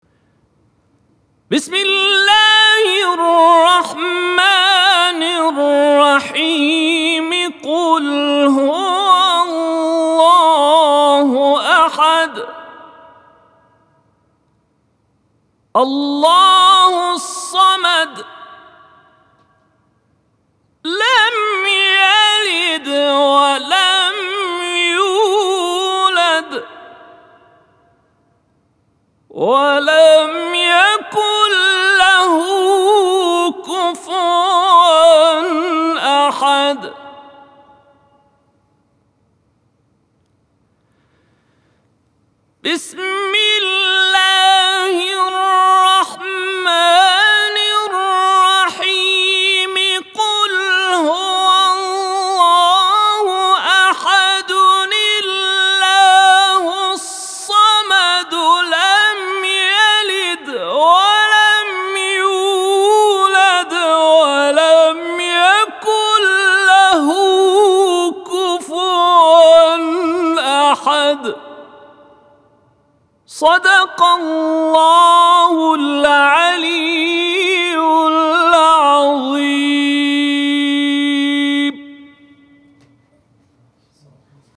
این تلاوت در افتتاحیه استودیو مذهبی شمال‌ غرب کشور در شهر تبریز ضبط شده است.